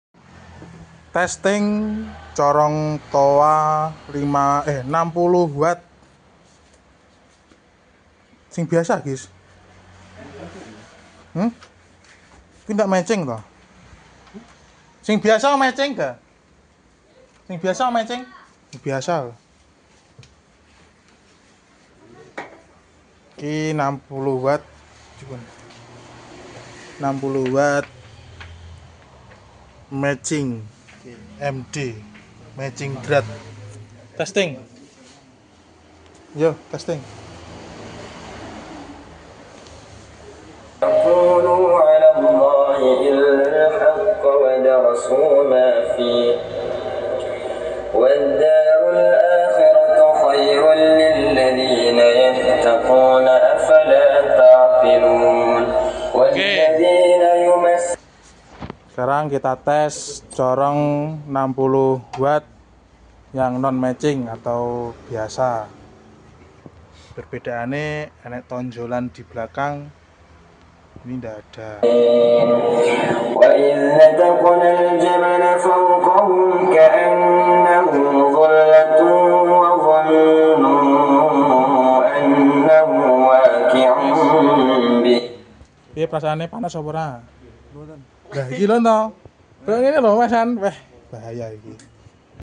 test Ting corong toa balap sound effects free download
test Ting corong toa balap 60wat mecing dan nonmecing corong se kabupaten